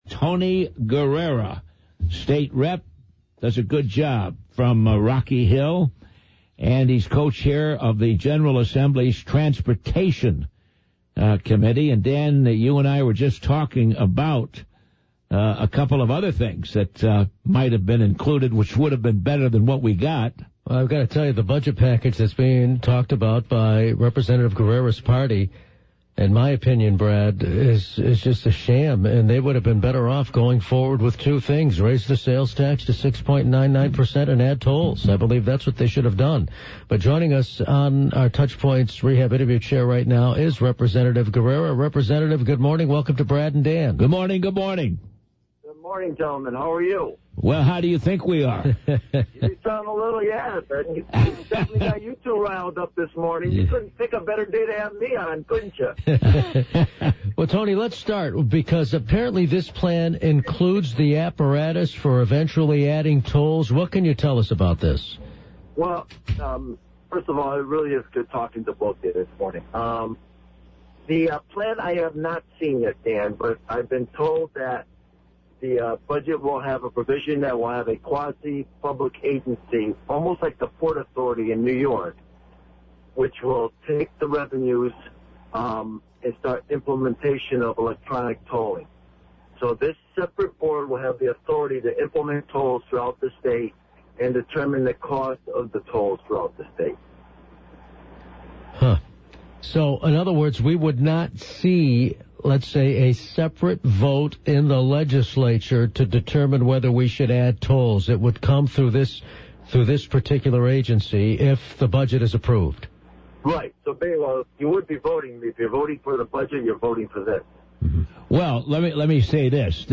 That's if the budget includes Gov. Malloy's proposal for a quasi-government transportation agency. The agency would have the power to add tolls to Connecticut roads. Rep. Guerrera talks about that and more in this interview.